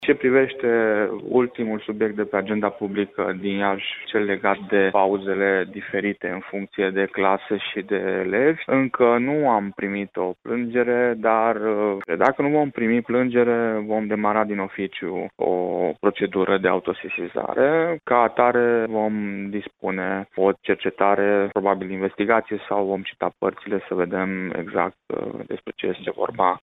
Preşedintele Consiliului Național pentru Combaterea Discriminării, Astalosz Csaba, a declarat, pentru postul nostru de radio, că pînă în prezent nu a fost depusă o plângere referitoare la acest caz:.